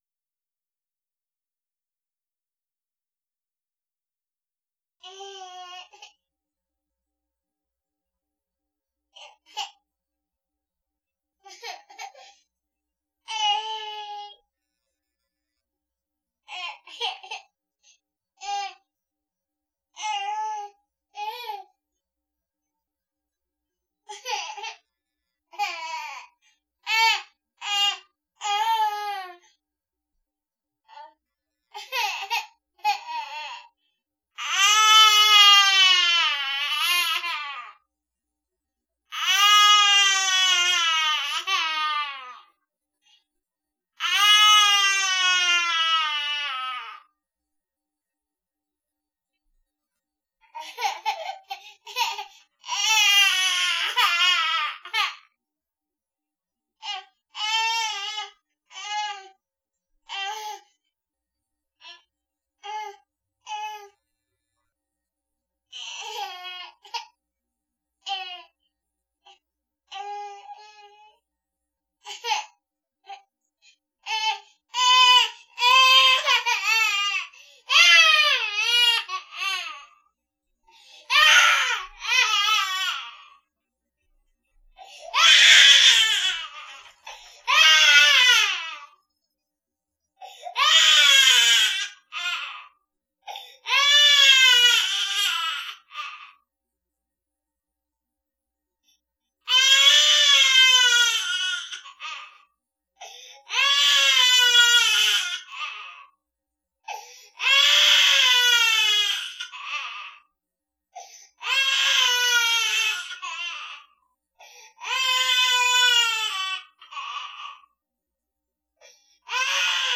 Crying Baby
Child_crying.wav